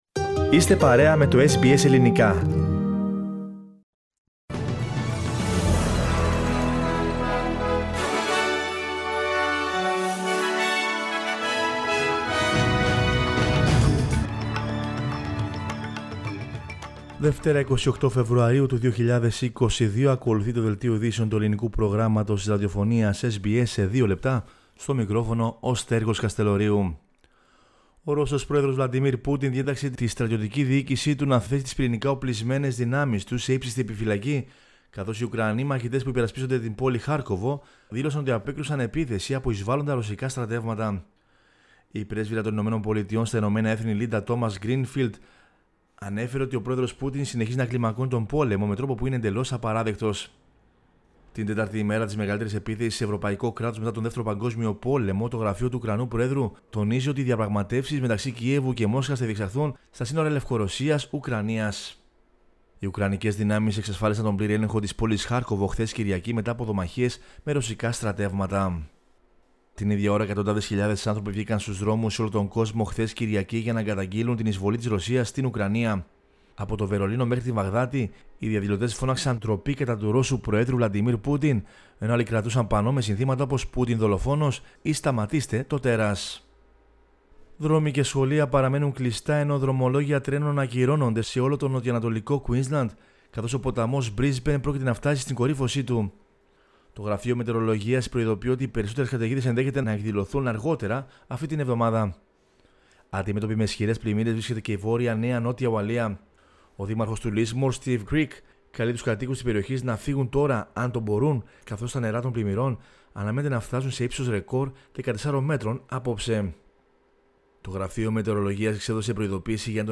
The main news headlines from Australia, Greece, Cyprus and the world.